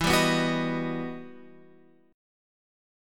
E Minor Major 9th